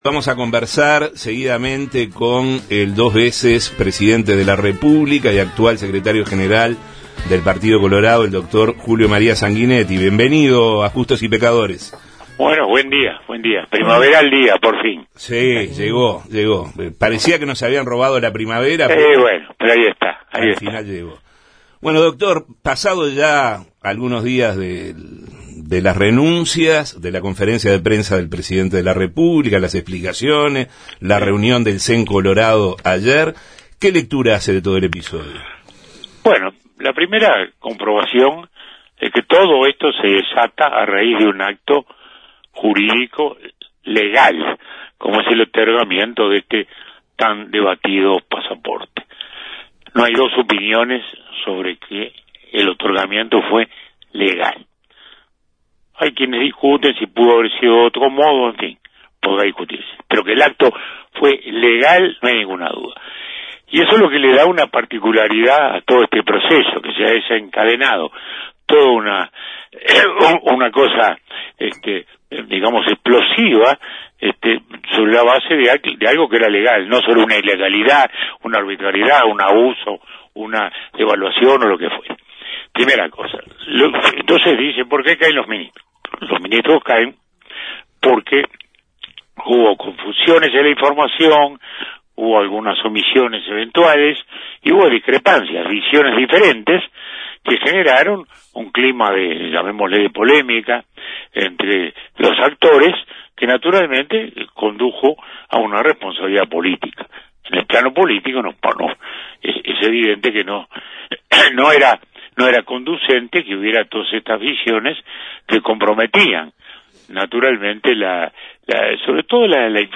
En Justos y pecadores entrevistamos al expresidente Julio María Sanguinetti, secretario general del Partido Colorado